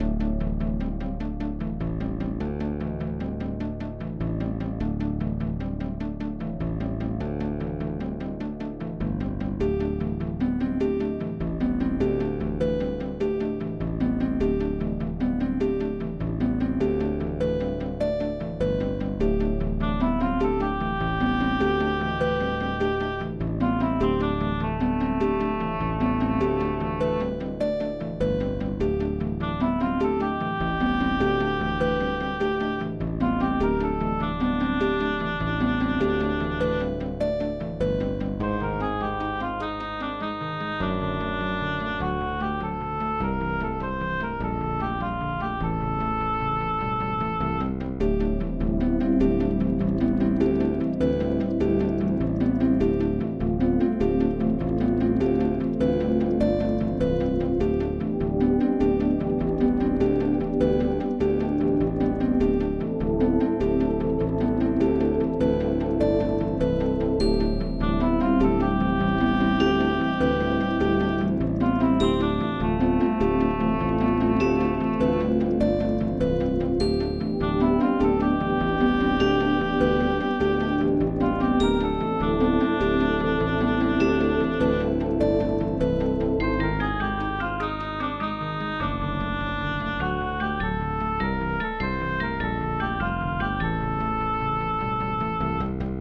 Perfect for levels with a somewhat mysterious mood, like a forest or underground.